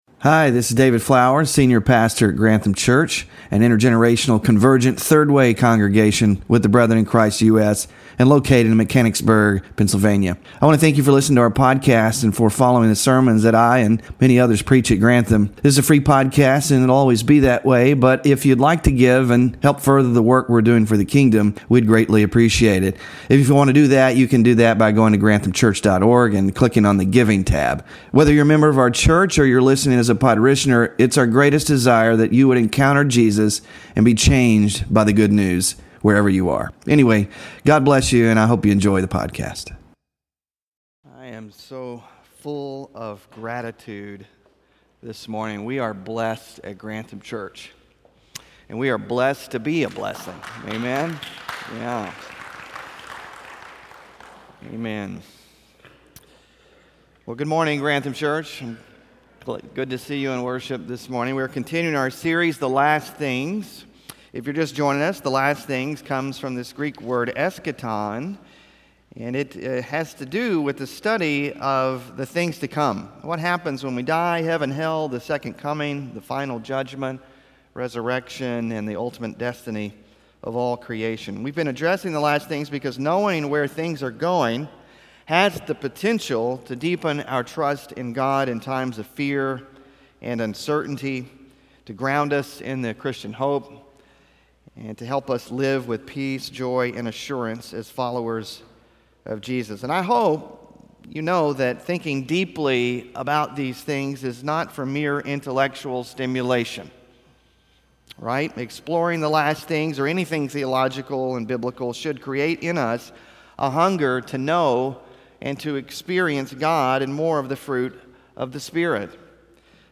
THE FINAL JUDGMENT & THE RESTORATION OF THE COSMOS – SERMON